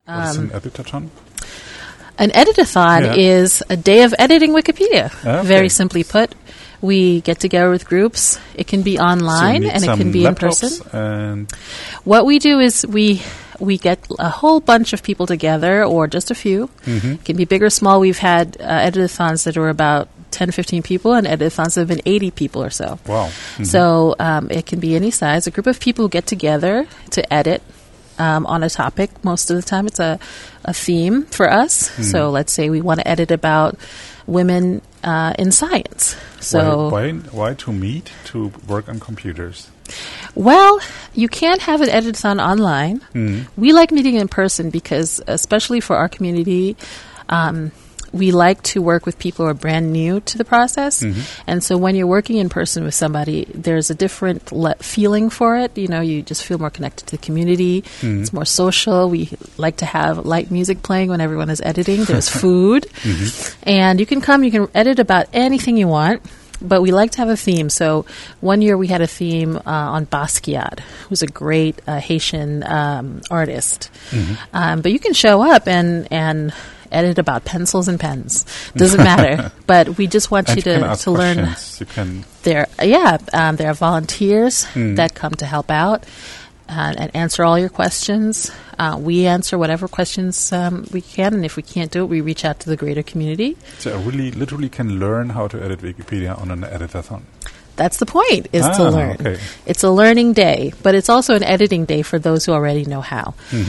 에디터톤을 설명하고 있다.